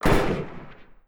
fire_dist_REPLACEME.wav